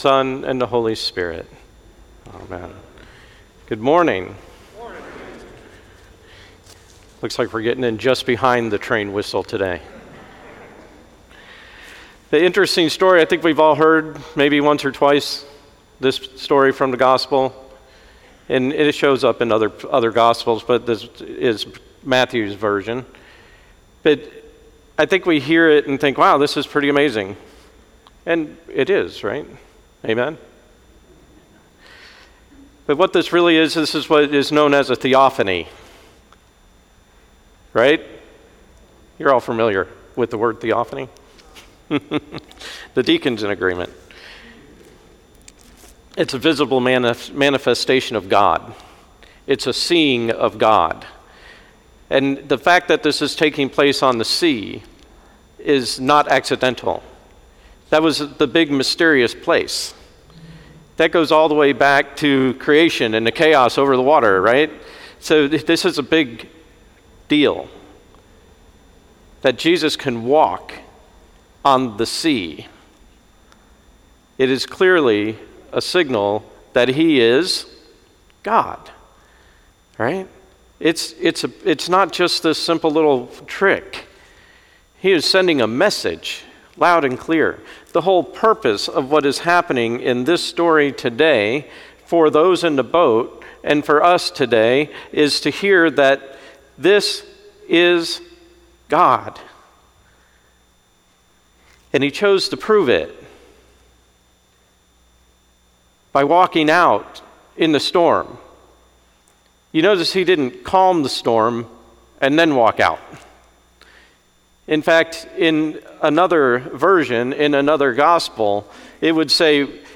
Sermon 8.9.20 Tenth Sunday after Pentecost. Holy Innocents' Episcopal Church, Valrico Florida
Sermon 8.9.20 Tenth Sunday after Pentecost